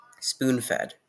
Ääntäminen
Ääntäminen US Tuntematon aksentti: IPA : /spun ˈfɛd/ Haettu sana löytyi näillä lähdekielillä: englanti Käännöksiä ei löytynyt valitulle kohdekielelle.